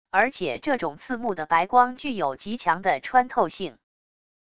而且这种刺目的白光具有极强的穿透性wav音频生成系统WAV Audio Player